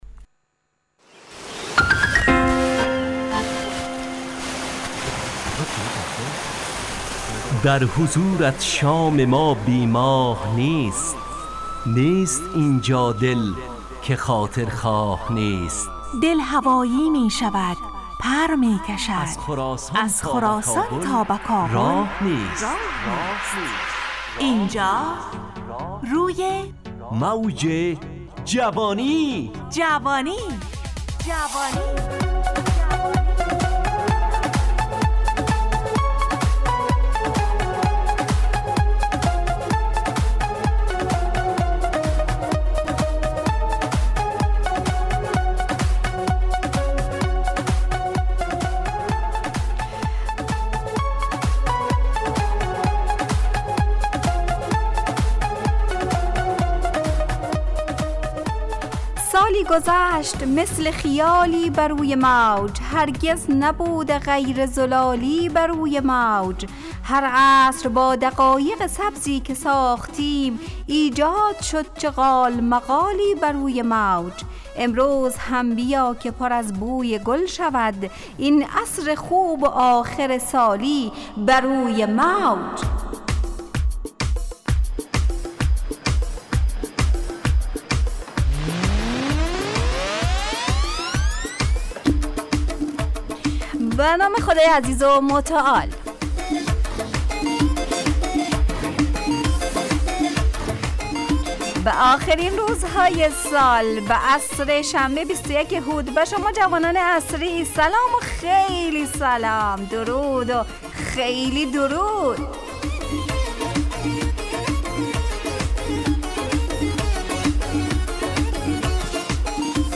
روی موج جوانی، برنامه شادو عصرانه رادیودری. از شنبه تا پنجشنبه ازساعت 17 الی 17:55 طرح موضوعات روز، وآگاهی دهی برای جوانان، و.....بخشهای روزانه جوان پسند....
همراه با ترانه و موسیقی .